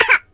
project_files/HedgewarsMobile/Audio/Sounds/voices/Surfer/Ooff3.ogg
Ooff3.ogg